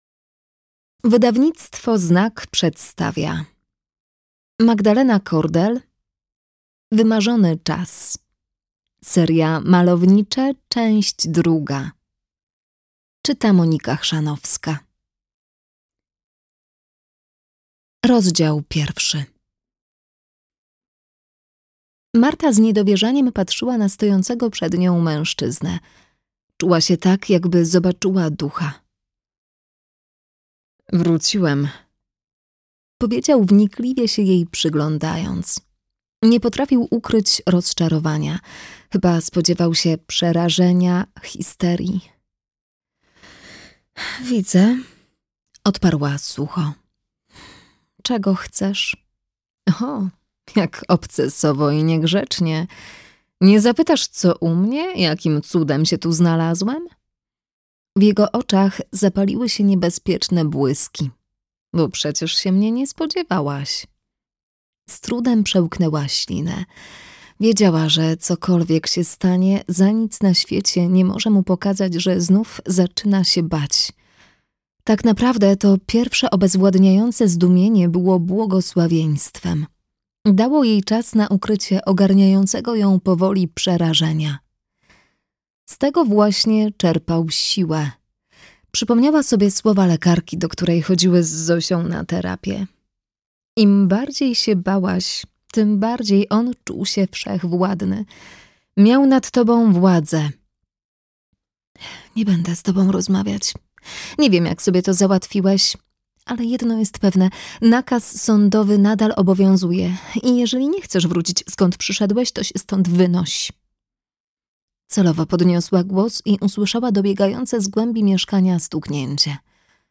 Malownicze. Wymarzony czas - Magdalena Kordel - audiobook